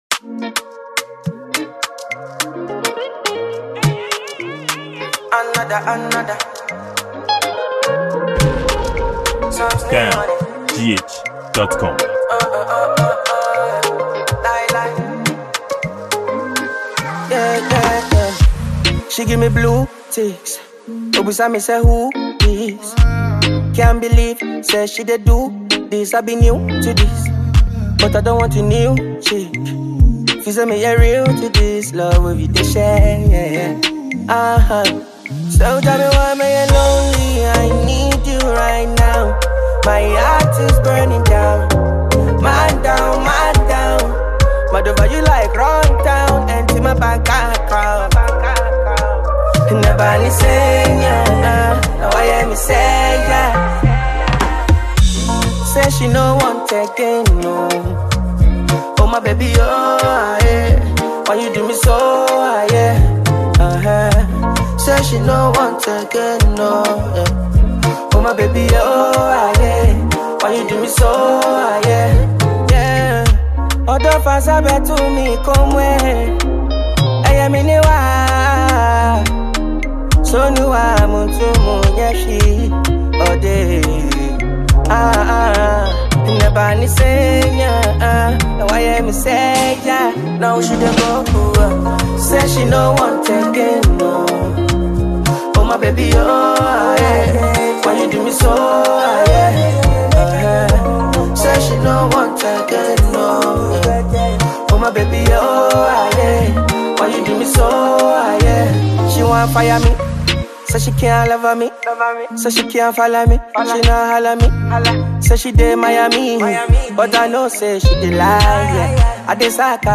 a Ghanaian afrobeats singer and songwriter